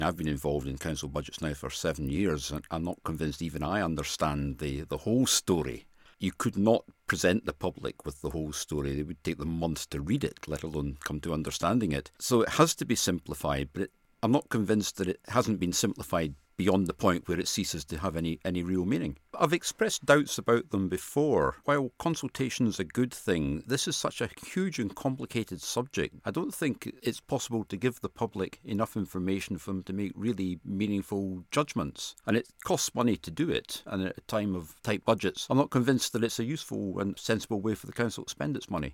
However, Tory Councillor Dave Dempsey suggests the whole exercise costs time, effort and money without much chance for meaningful answers: